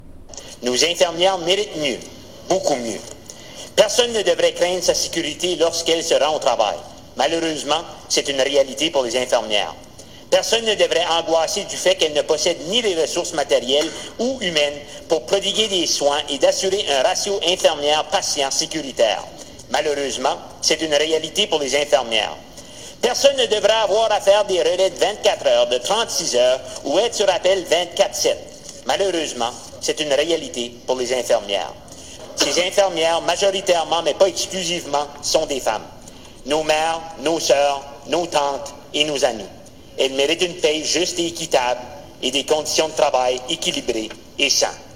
Kevin Arseneau s'est adressé au président de l'Assemblée législative, mercredi.
Le député vert a déclaré que les infirmières du Nouveau-Brunswick méritent mieux :